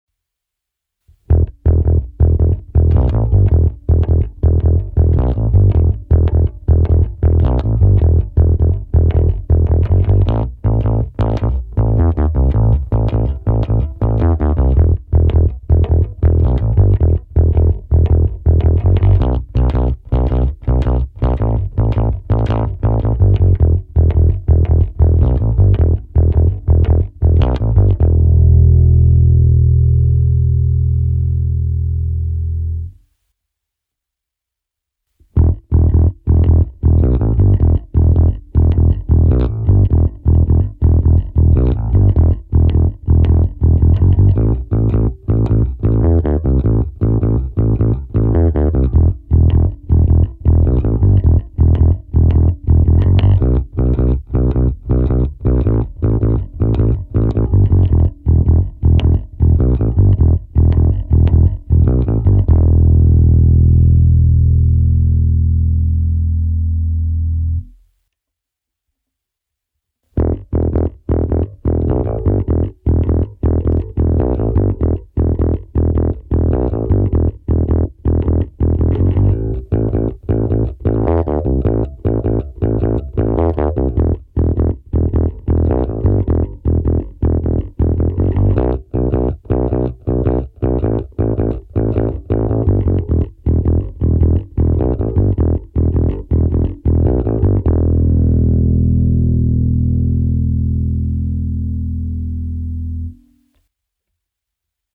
V náhrávkách je mix čistého zvuku se simulací aparátu v softwaru Overloud TH3. Pořadí ukázek: krkový snímač - oba snímače - kobylkový snímač. Plně otevřené tónové clony, vypnuté zvýrazňovače středů, u trsátkové ukázky zapnutý zvýrazňovač atacku.
Prsty